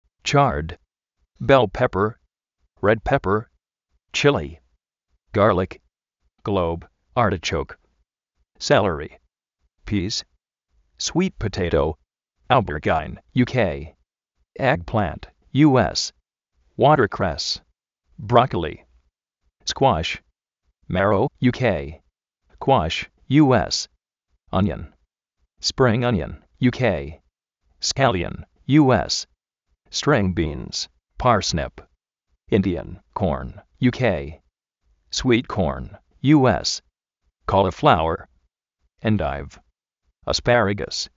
bél péper, red péper
gárlic
(glóub) árti-chóuk
oberyáin
kóli-fláuer